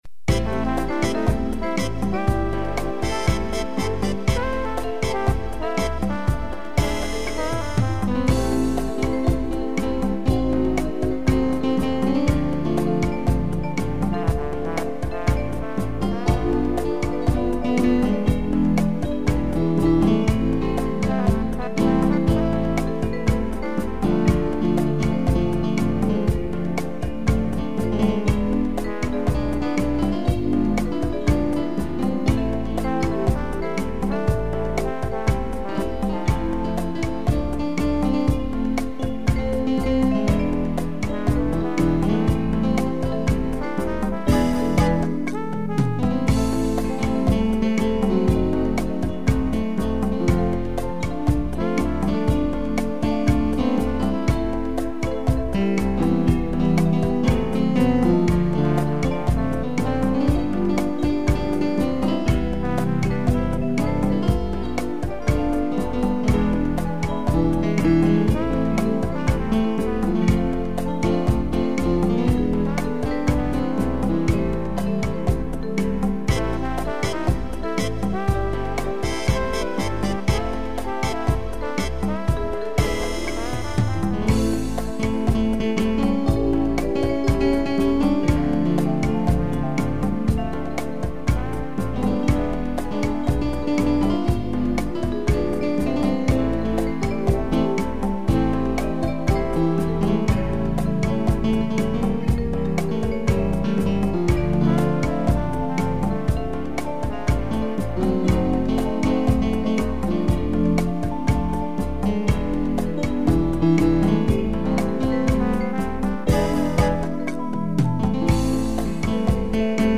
vibrafone e trombone
instrumental